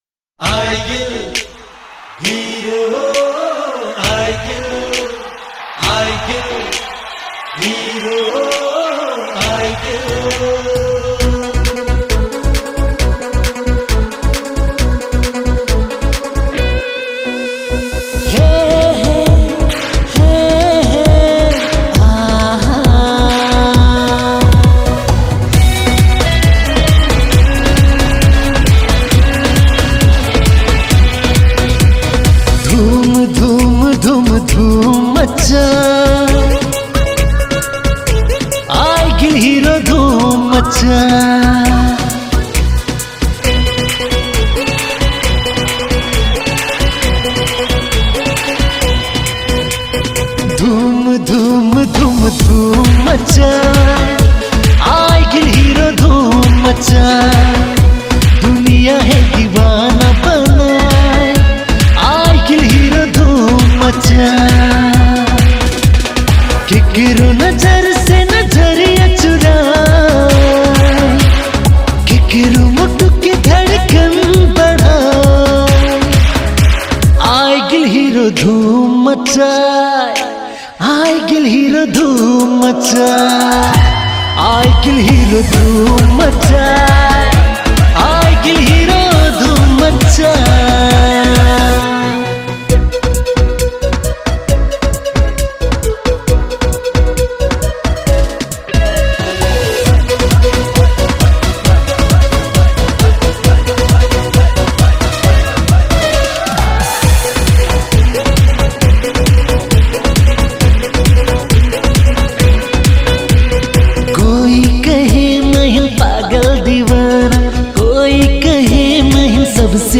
Tharu Item Dancing Song